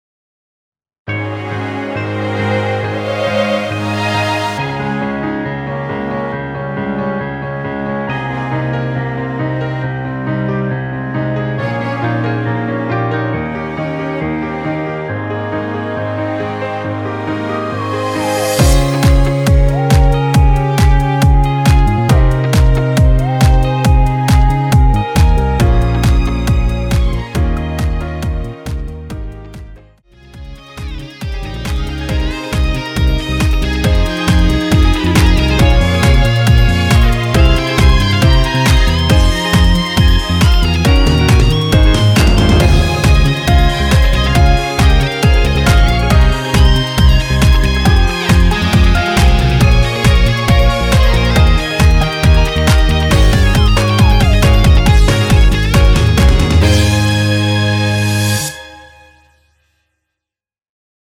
원키에서 (+4)올린 MR 입니다.
엔딩이 페이드 아웃이라?노래 하시기 좋게 엔딩을 만들어 놓았으니 미리듣기 참조 하세요.
Ab
앞부분30초, 뒷부분30초씩 편집해서 올려 드리고 있습니다.